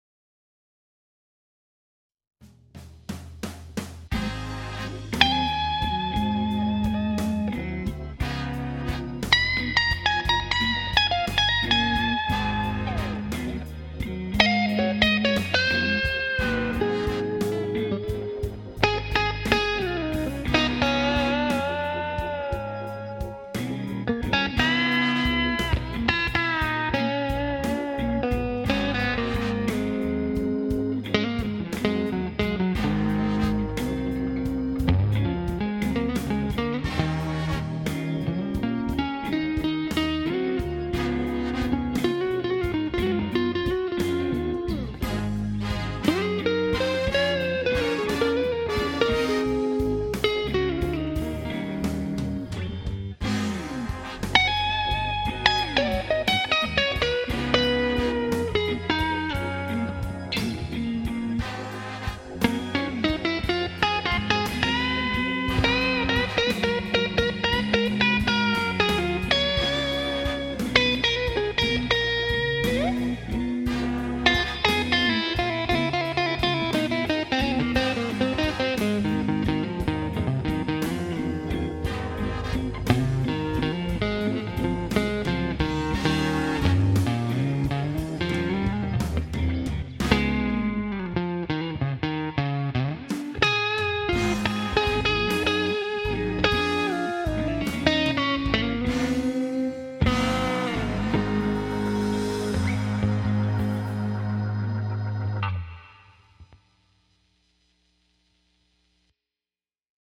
Guitar Works